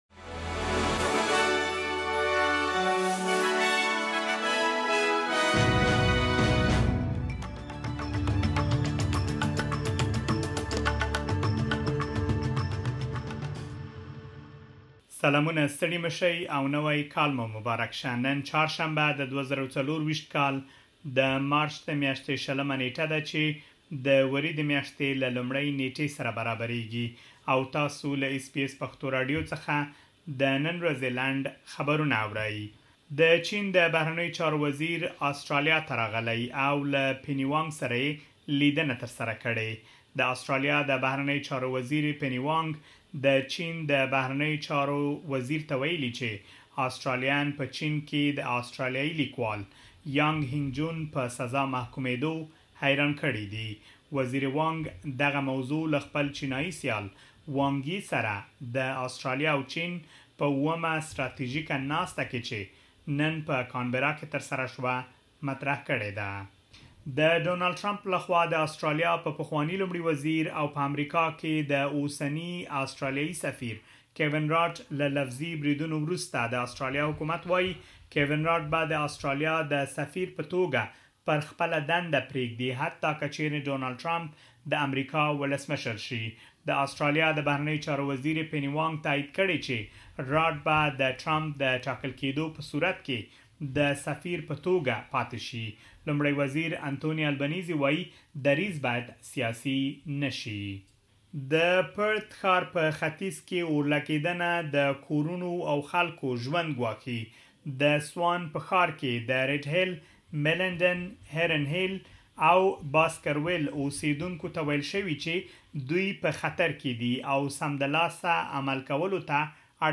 اس بي اس پښتو راډیو د نن ورځې لنډ خبرونه دلته واورئ.